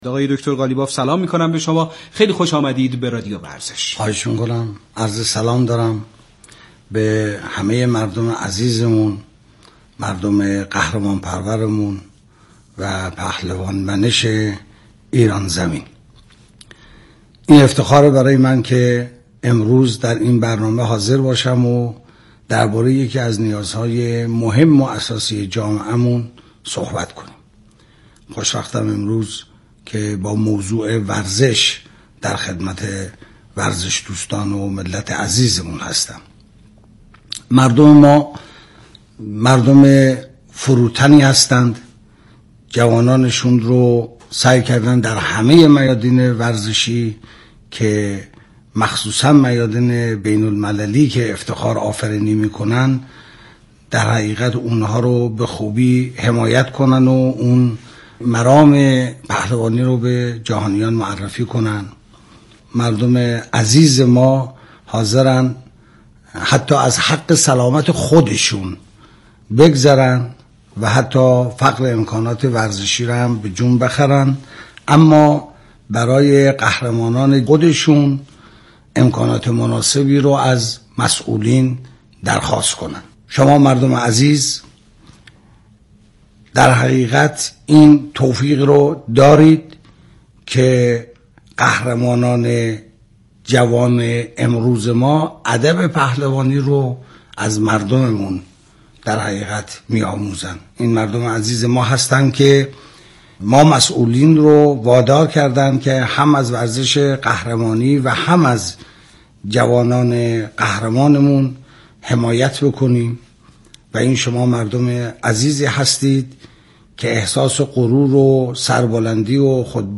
به گزارش ایسنا، محمدباقر قالیباف کاندیدای انتخابات ریاست جمهوری دوازدهم ظهر امروز (سه شنبه) در برنامه صدای انتخاب در گفت‌وگو با رادیو ورزش به ارائه توضیحاتی درباره برنامه های خود در حوزه ورزش پرداخت.